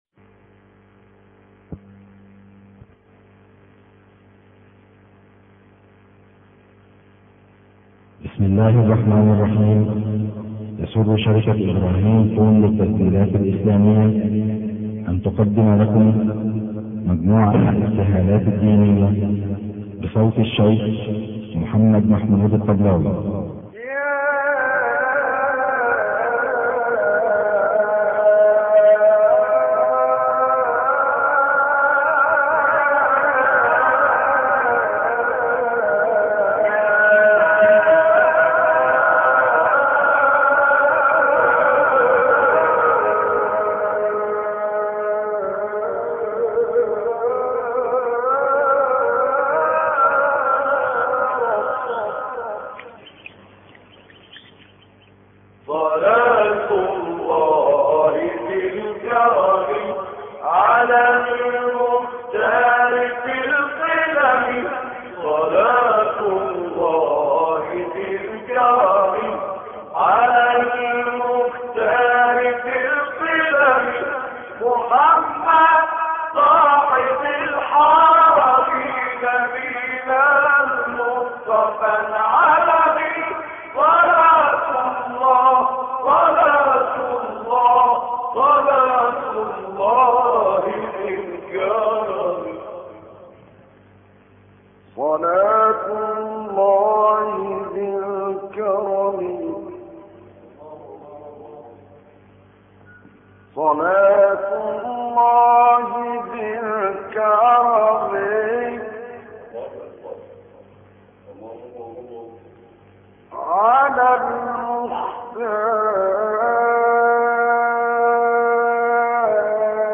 دعاء المبتهل الداعي الشيخ محمد محمود الطبلاوي تقبل الله منه لإبتهال صلاة الله ربي ذي الكرم على المختار في القدم